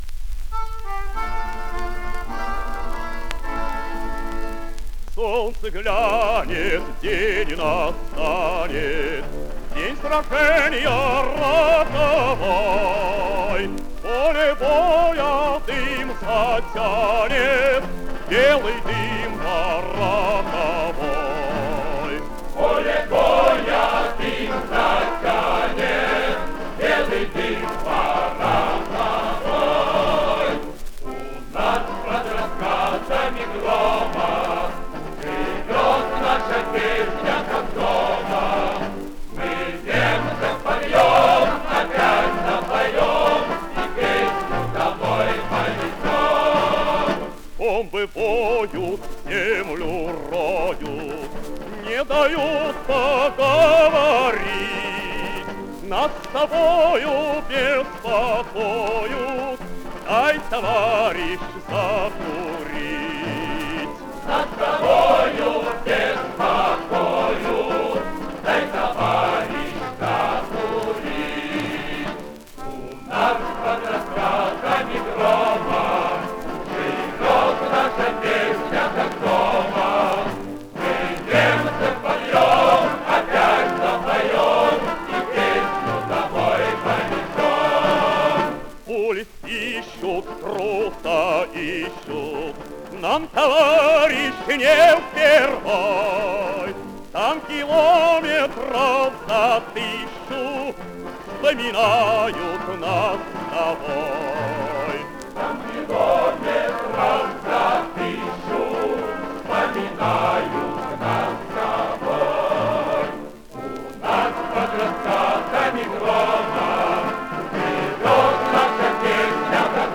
Малоизвестная песня военных лет в лучшем качестве.